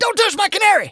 tnt_guy_hurt_02.wav